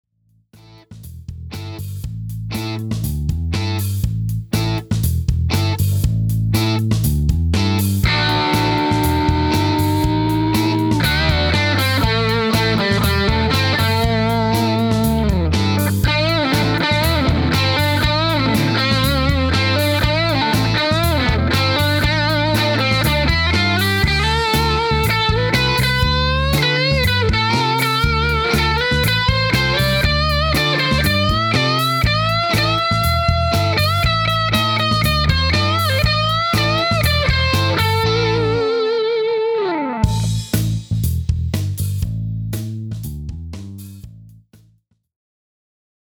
Both clips were recorded with my Aracom VRX22 and my ’59 Les Paul replica plugged straight into the amp. I only added a touch of small room reverb in my DAW to give the tone a bit of grease:
Dirty Lead